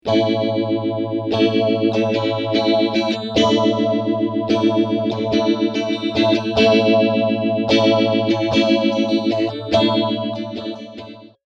Rate 50%, Recycle 50% (115k)
Clips were recorded:  Pretty guitar - effect - Bulldog Cab Sim - sound card